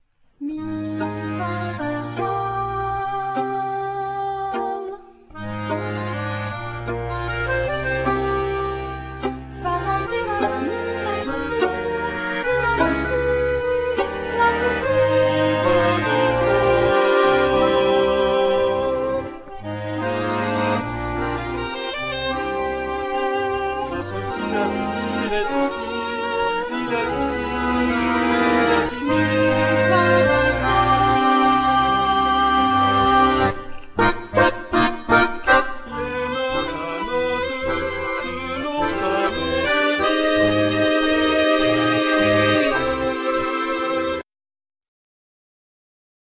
Accordeon,Vocals
Violin,Viola,Vocals
Cello
Drums,Percussions